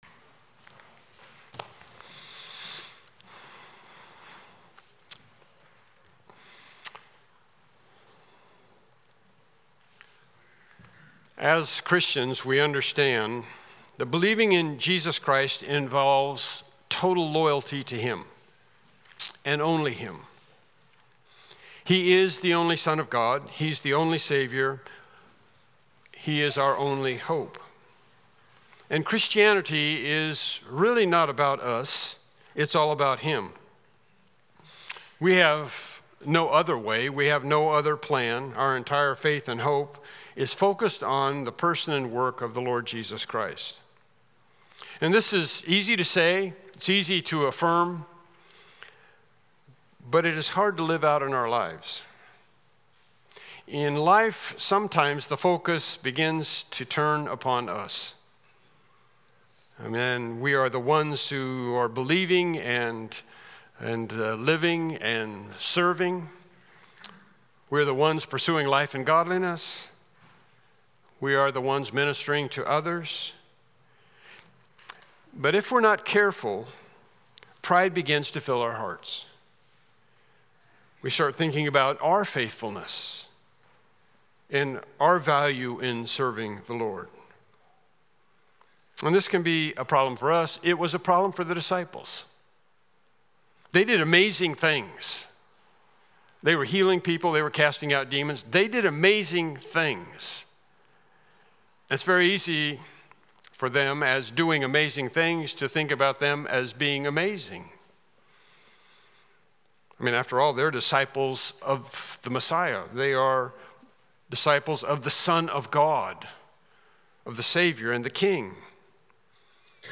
Mark Passage: Mark 9:34-37 Service Type: Morning Worship Service « Lesson 19